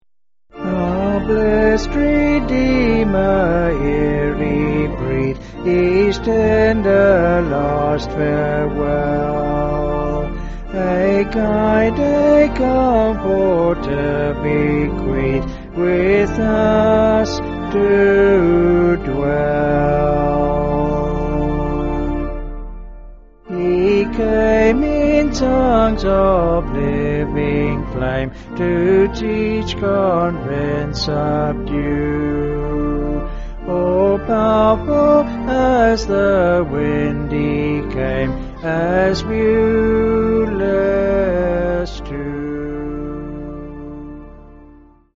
(BH)   6/Dm
Vocals and Organ